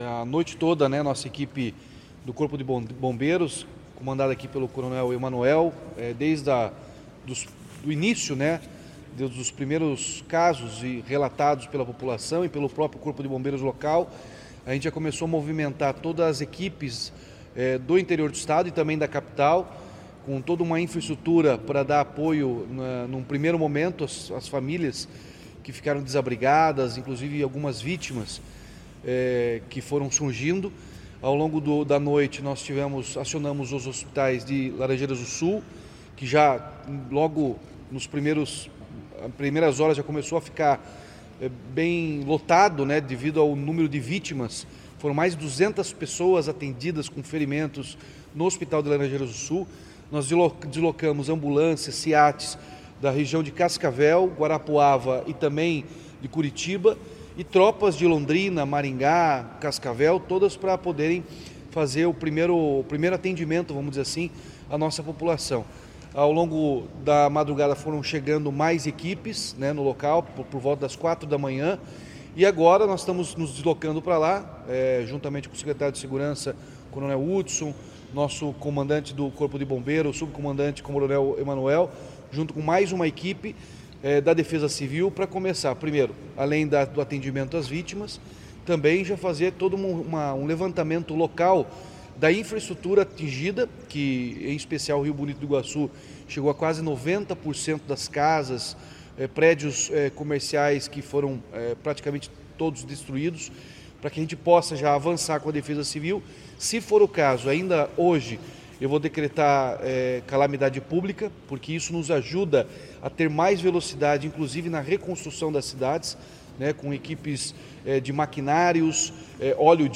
Sonora do governador Ratinho Junior sobre as medidas adotadas pelo Estado no atendimento às vítimas do Tornado